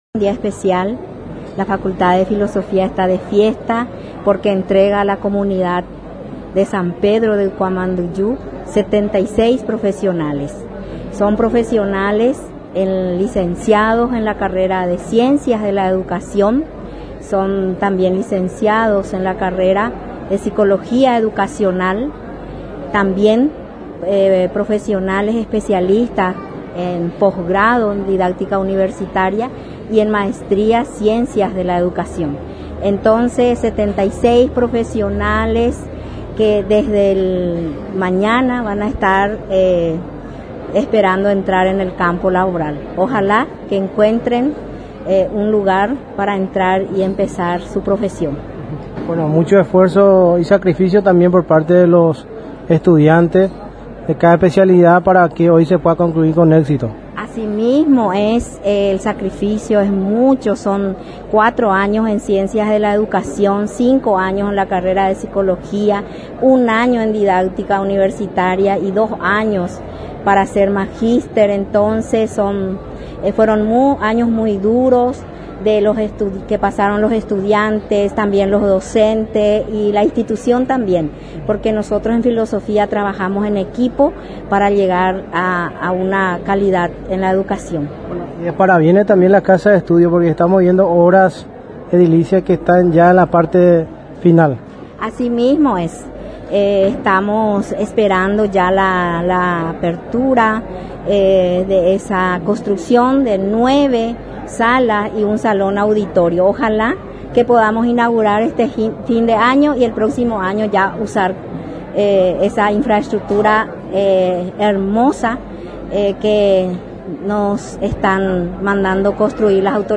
ACTO-DE-GRADUACION.mp3